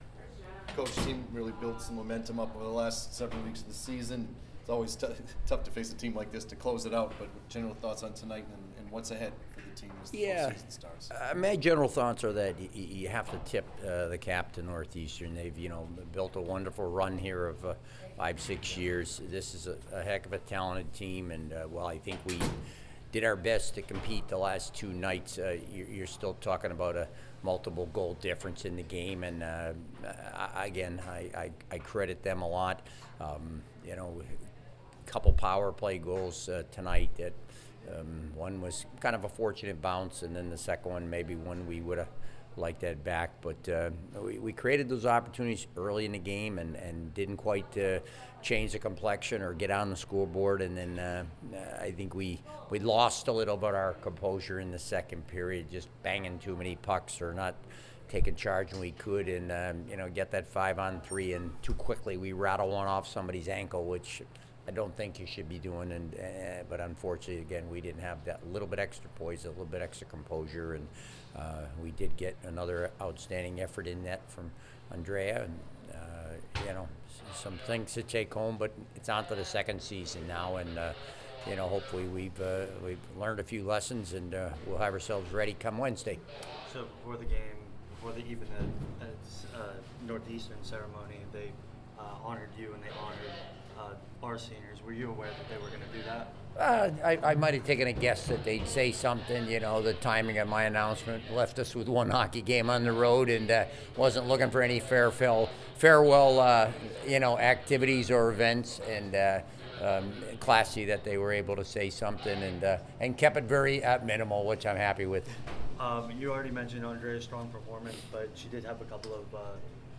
Women's Ice Hockey / Northeastern Postgame Interview